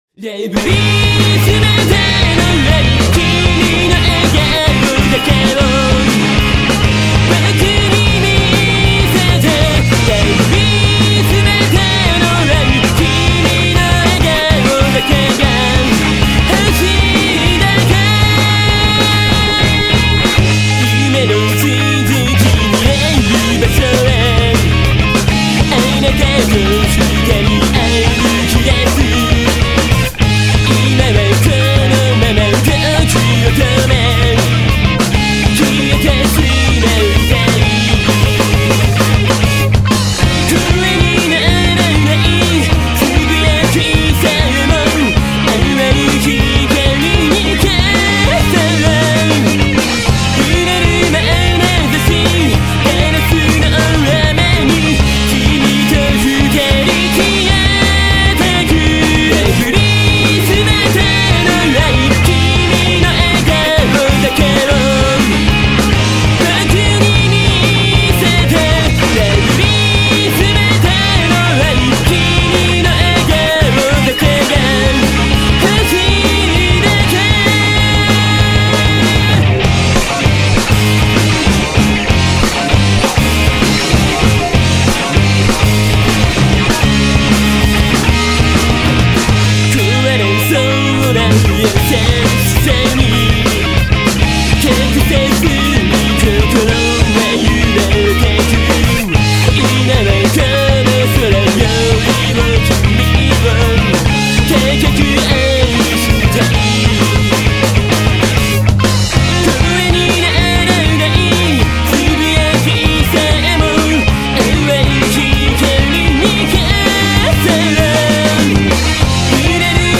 Visual Kei